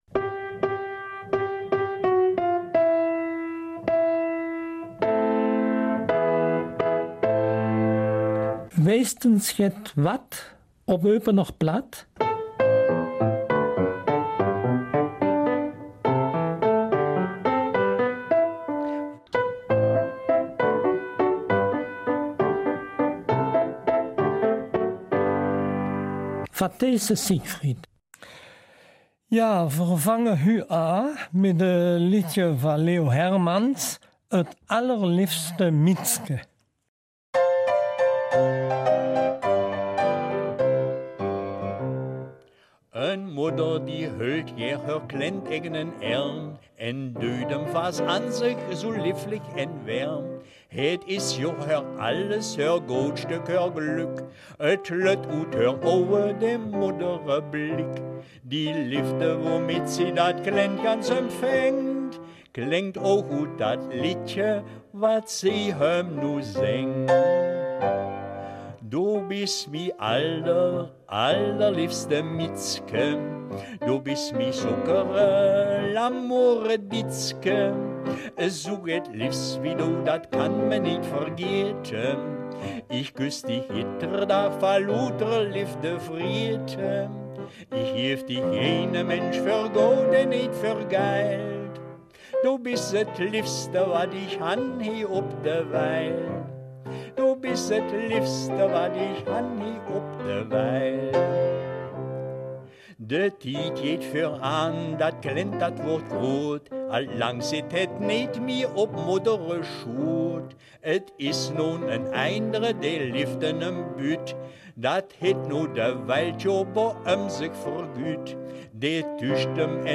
Eupener Mundart - 9.